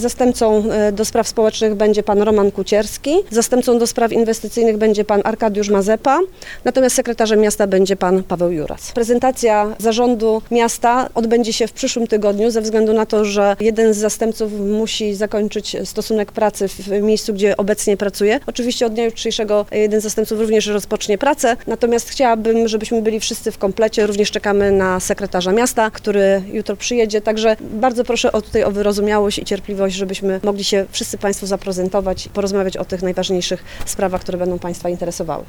Nowa prezydent Świnoujścia Joanna Agatowska złożyła dziś ślubowanie i rozpoczęła urzędowanie. Dopytywana przez dziennikarzy o nazwiska swoich zastępców odpowiedziała: